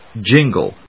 jin・gle /dʒíŋgl/
• / dʒíŋgl(米国英語)